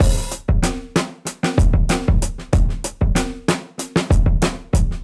Index of /musicradar/dusty-funk-samples/Beats/95bpm
DF_BeatB_95-03.wav